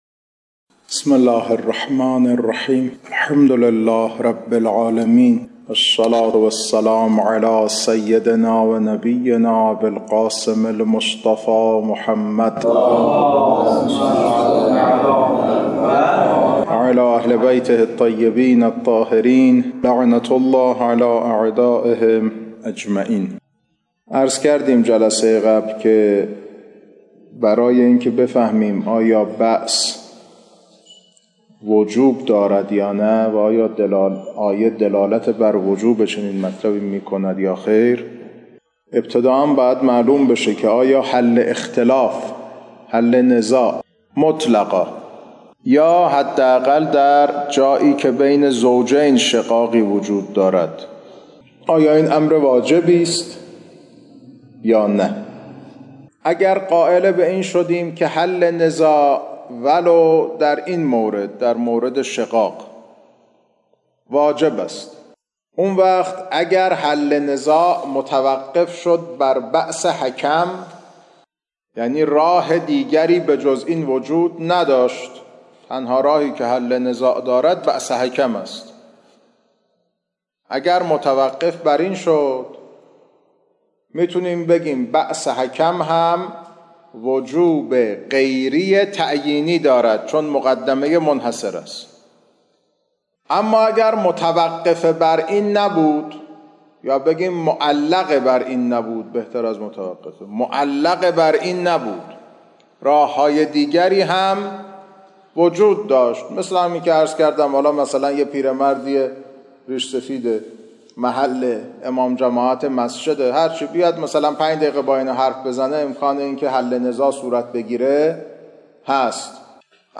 کلاس‌ها خارج فقه